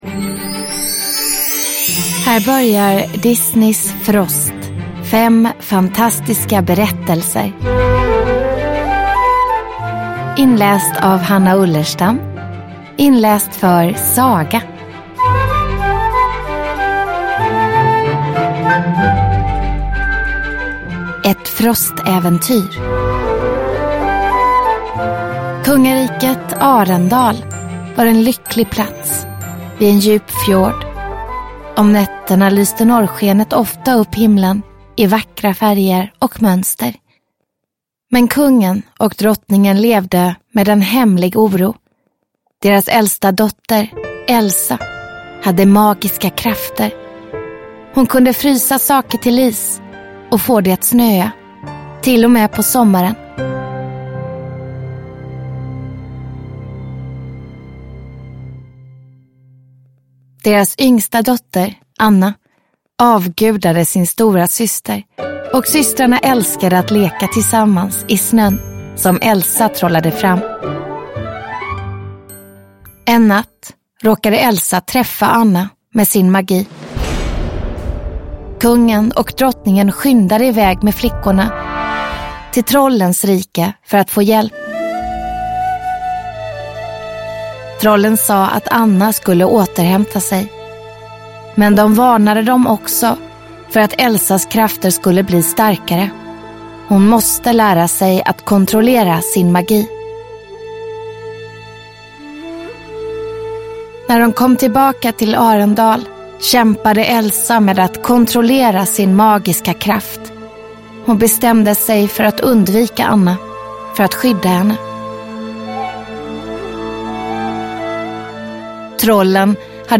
Ljudbok
I den här spännande sagoboken får du följa med Elsa, Anna och alla deras vänner på fem magiska äventyr i Arendal – nu med fantastisk musik och ljudeffekter!